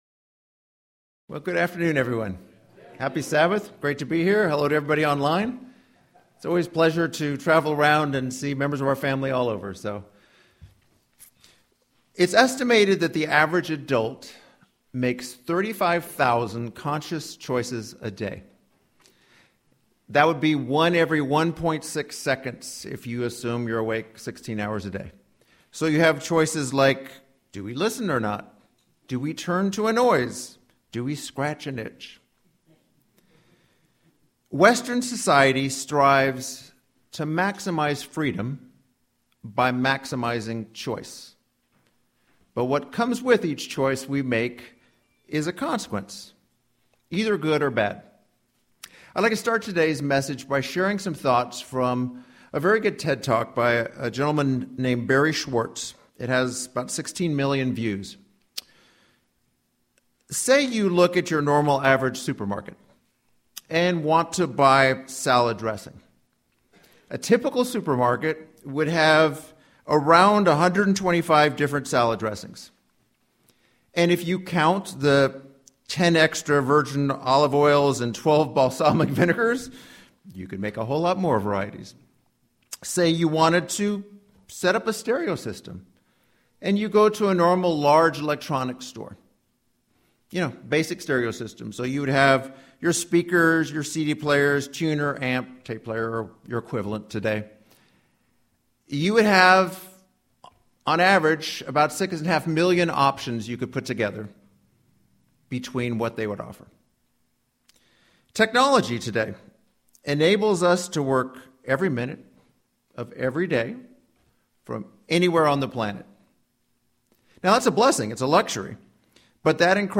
What skills can we learn to make better or more effective choices? We will explore this topic in this Sermon.